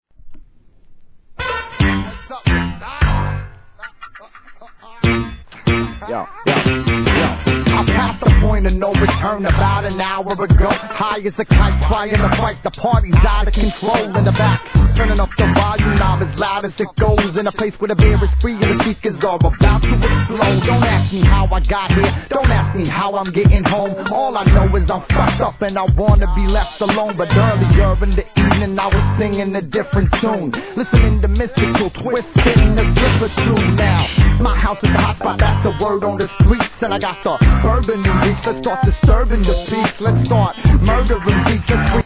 1. G-RAP/WEST COAST/SOUTH
サウス・サウンドのツボをきっちり捉えた手堅いスウェーデ発のBOUNCE!!